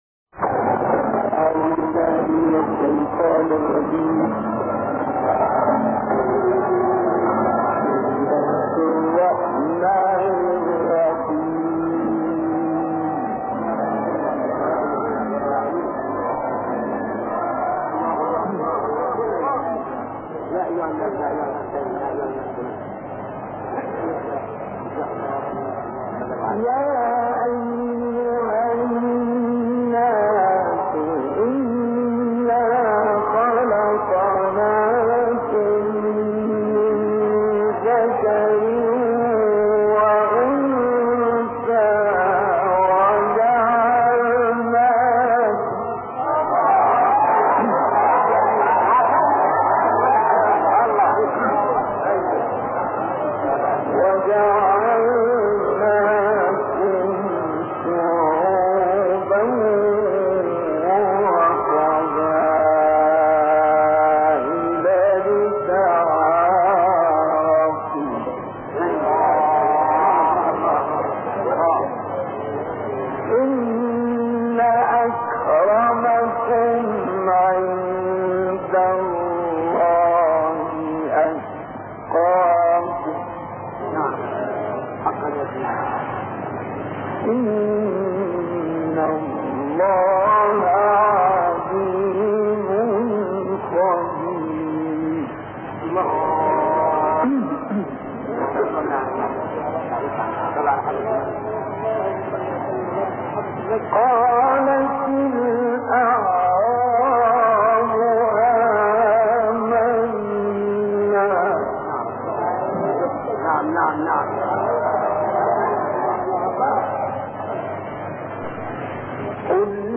تلاوت سوره حجرات و قاف مصطفی اسماعیل
توضیحات: تلاوت ناب و بسیار زیبا از استاد مصطفی اسماعیل